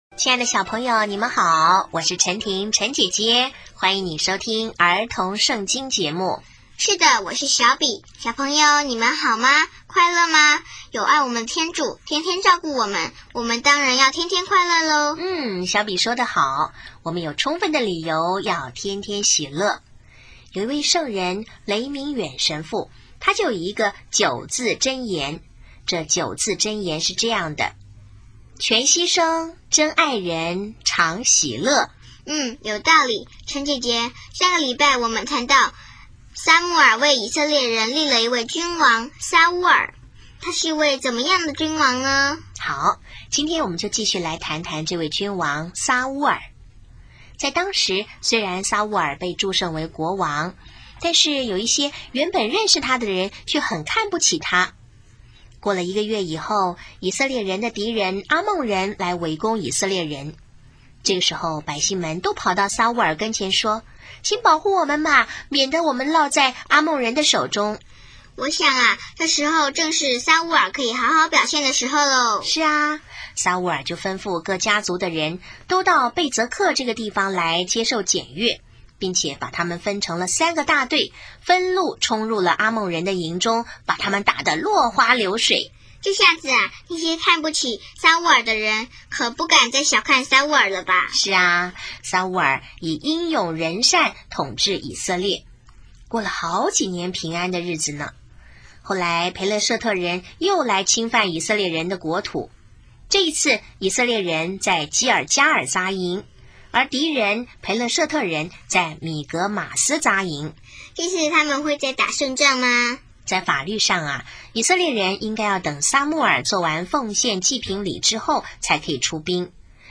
【儿童圣经故事】23|撒乌尔背离天主